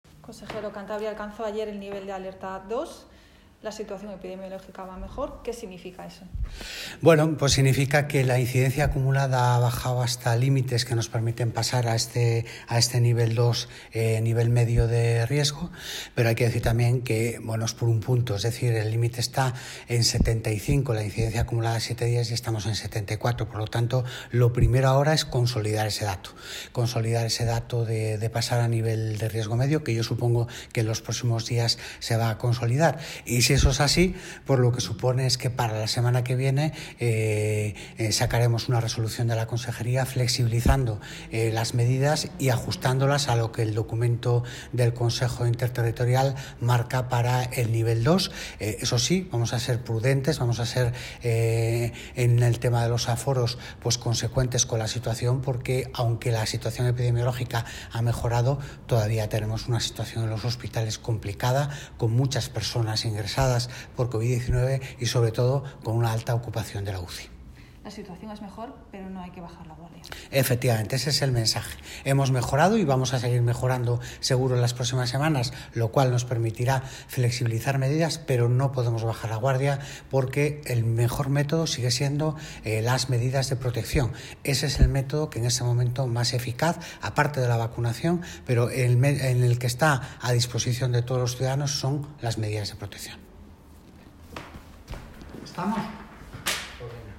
Audio del Consejero de Sanidad Cántabro